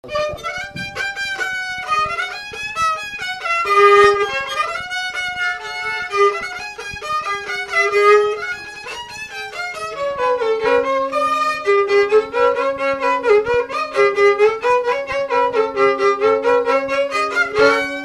Danse ronde
Instrumental
Pièce musicale inédite